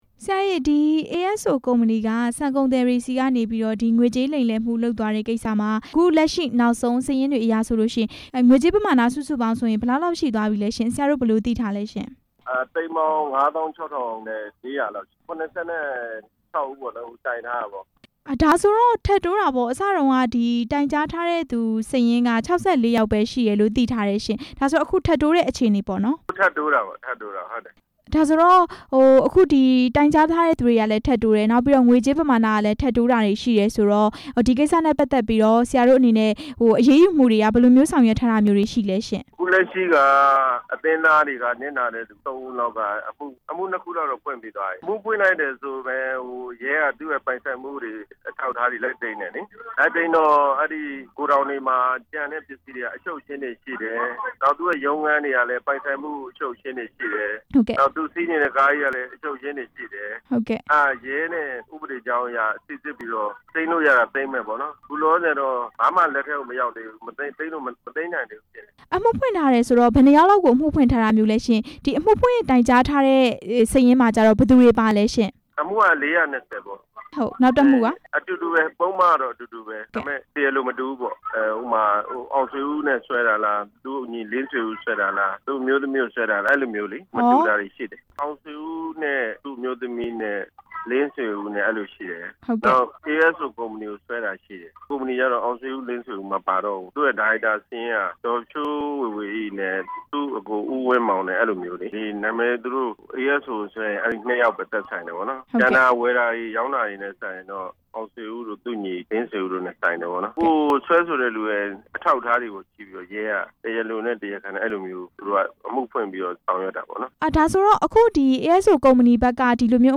ဆန်ဈေးကွက်အတွင်း လိမ်လည်မှုအကြောင်း မေးမြန်းချက်